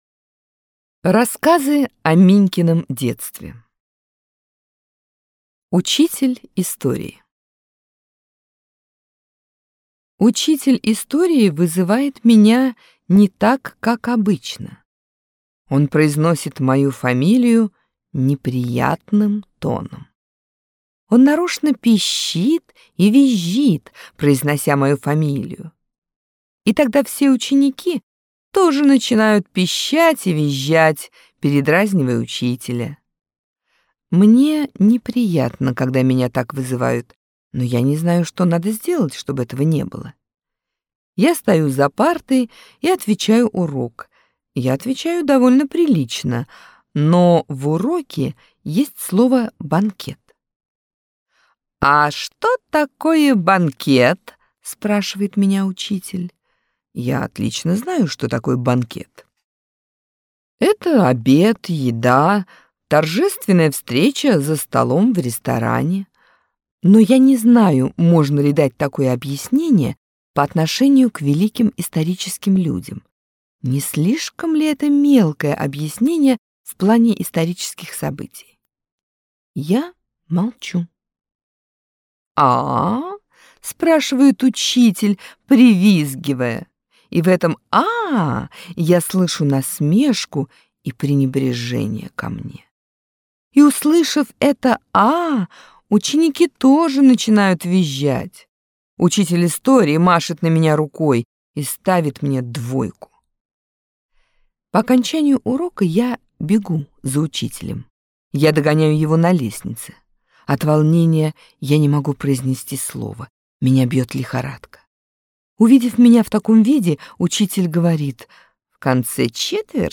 Аудиорассказ «Учитель истории»